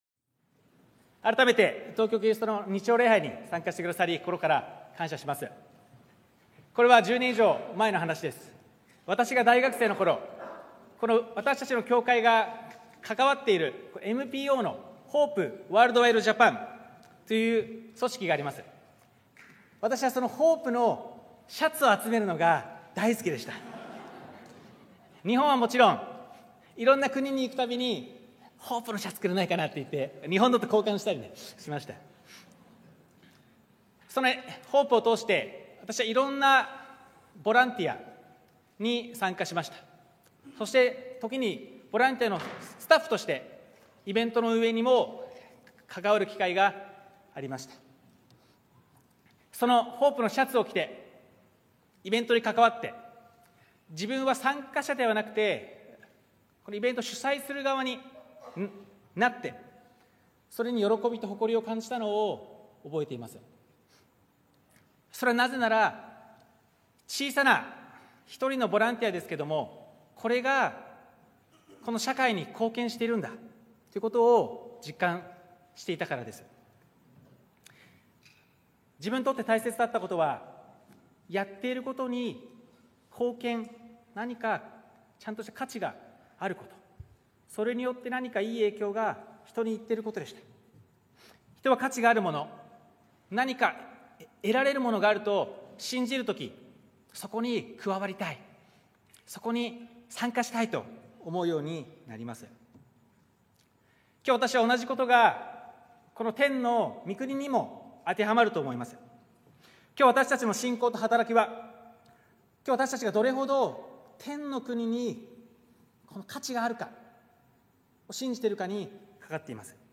東京キリストの教会 日曜礼拝説教